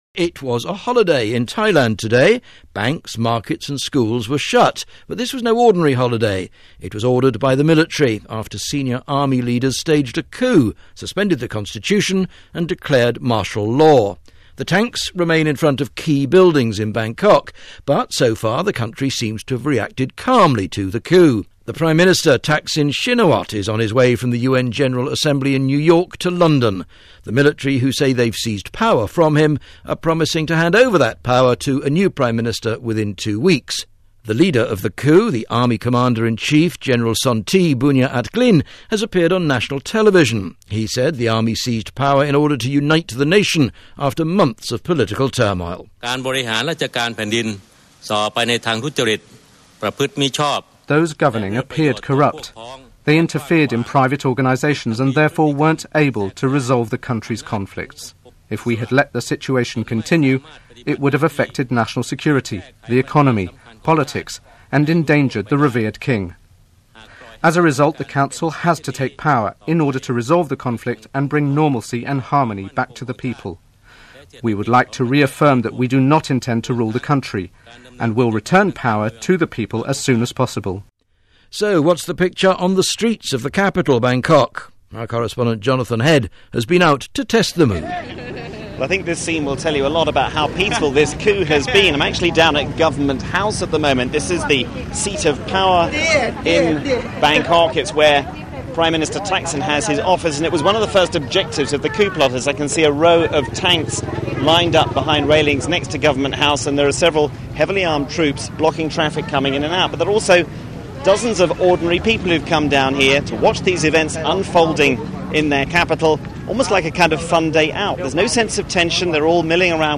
Click on the link here for Audio Player – BBC World Service Reports – September 19-20, 2006 – BBC World […]